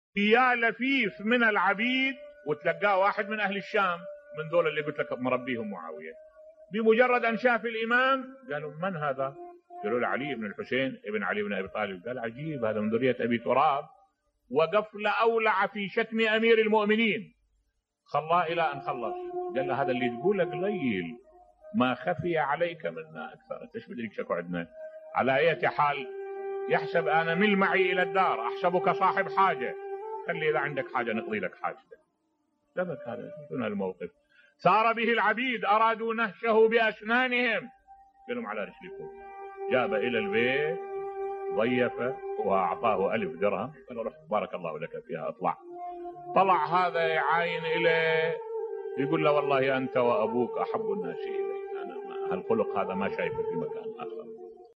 ملف صوتی أخلاق الإمام زين العابدين (ع) بصوت الشيخ الدكتور أحمد الوائلي